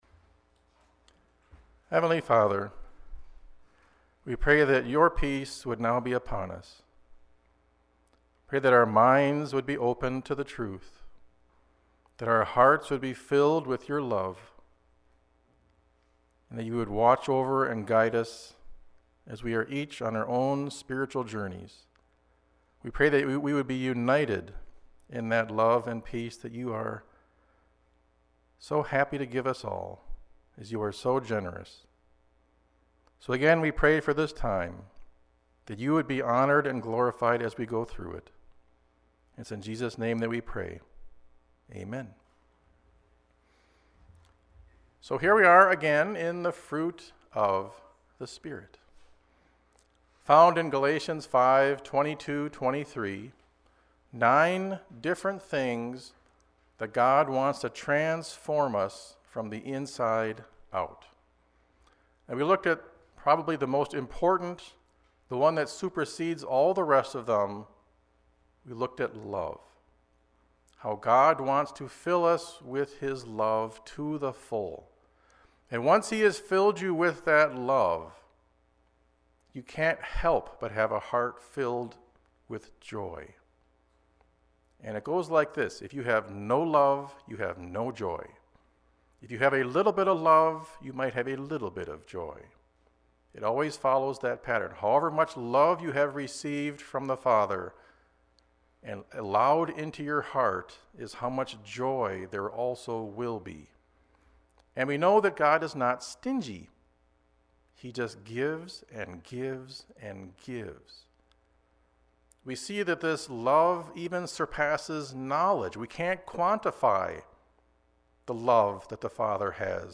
Sermon Series Twin Lakes